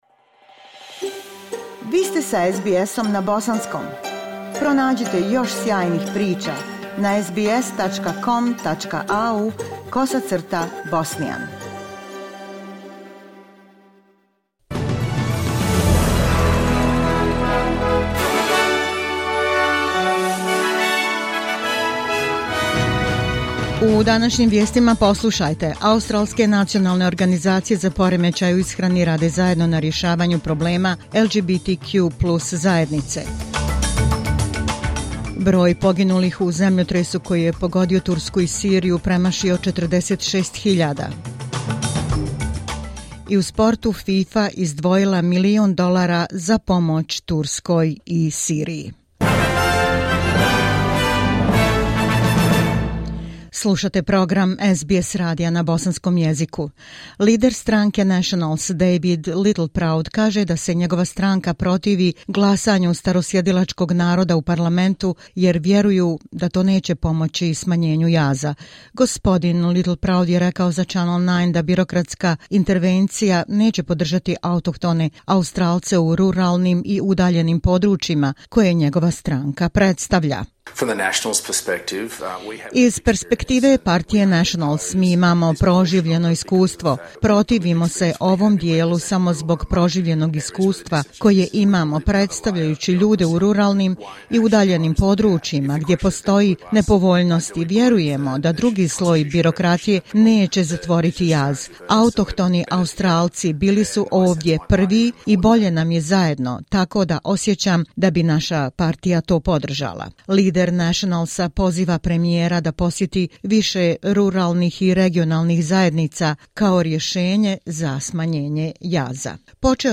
SBS radio news in Bosnian language.